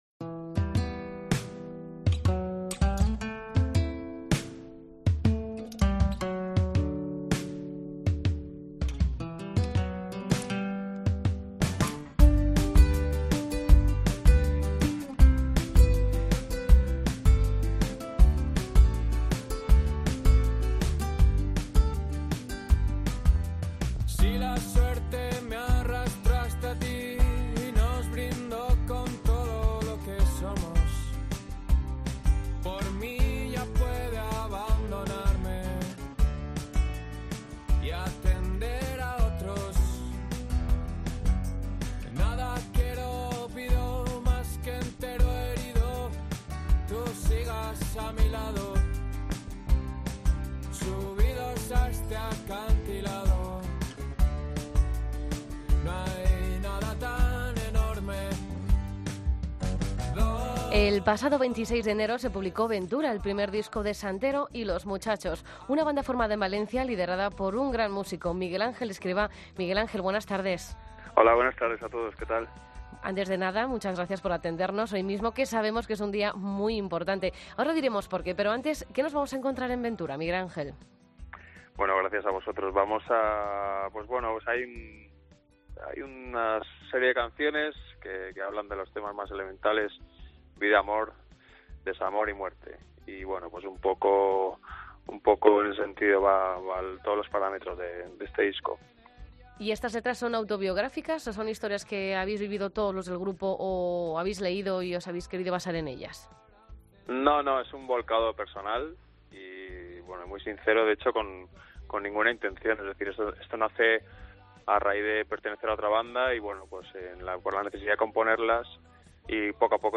Entrevis a Santero y Los Muchachos en La Linterna